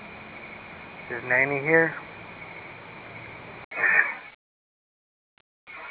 EVP's
(When this was cleared it was easy to hear, though when it was cut to add to this site as well as to a CD of EVP's it sped it up.)